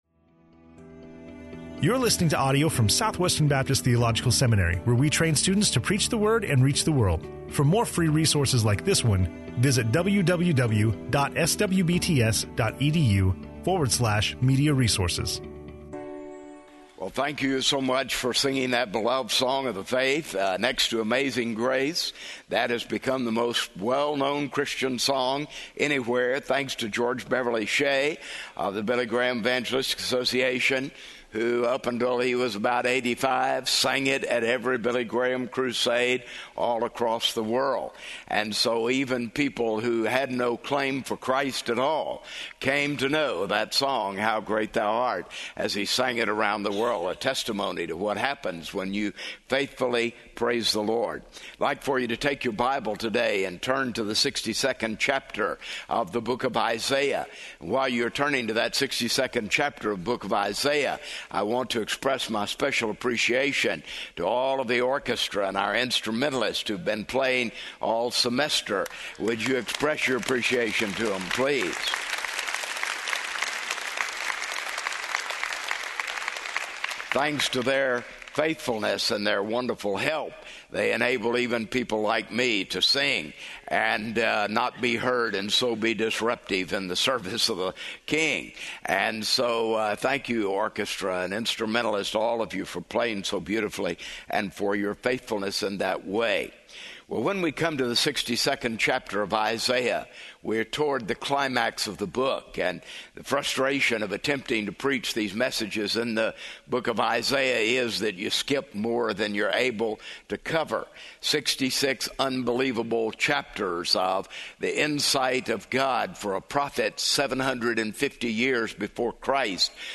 speaking on Isaiah 62:1-7 in SWBTS Chapel